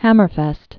(hămər-fĕst, hämər-)